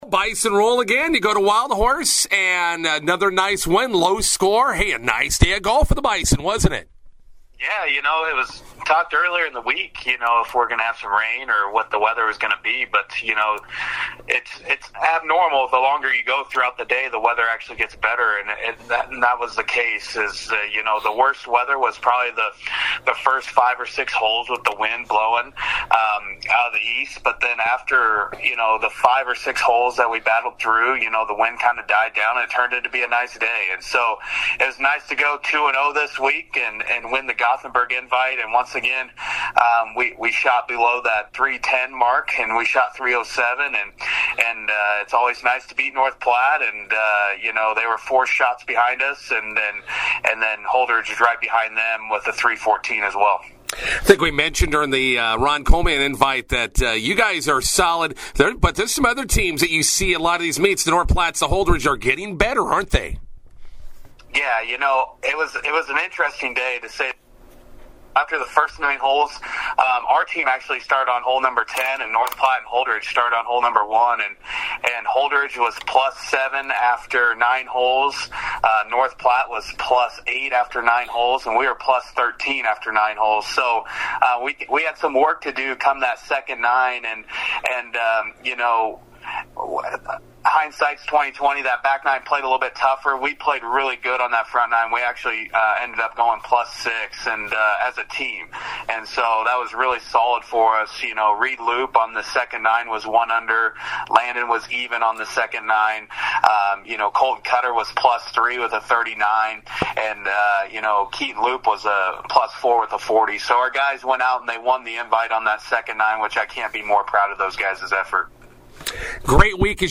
INTERVIEW: Bison golfers shoot 307 to win the Gothenburg Invite at Wild Horse.